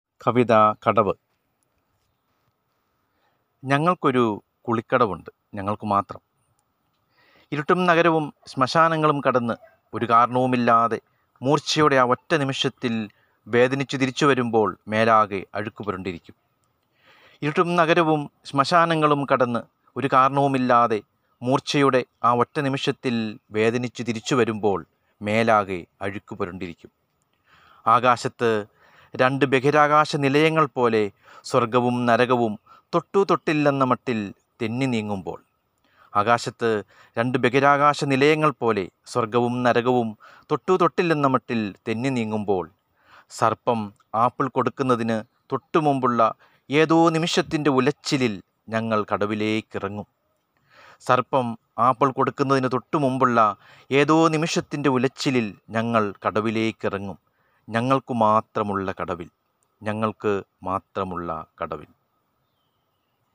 കവിത